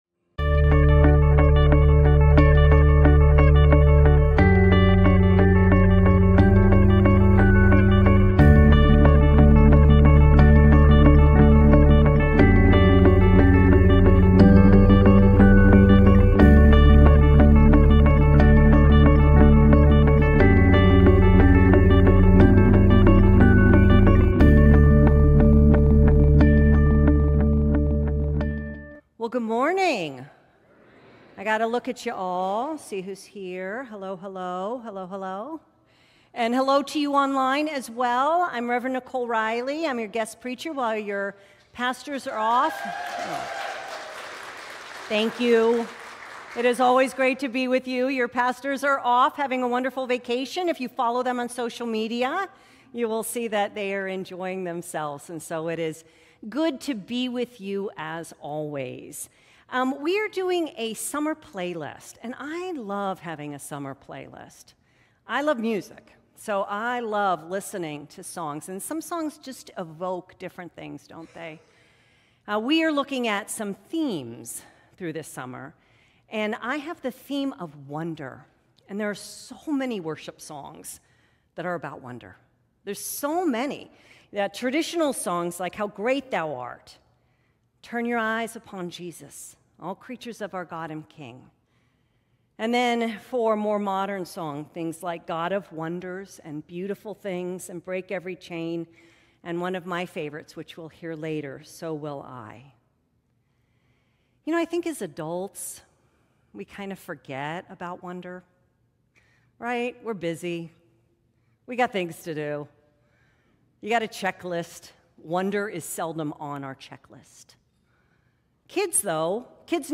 The weekly message from Valencia United Methodist Church in Santa Clarita, California.
Sermon Audio 7_28.mp3